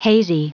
Prononciation du mot hazy en anglais (fichier audio)
Prononciation du mot : hazy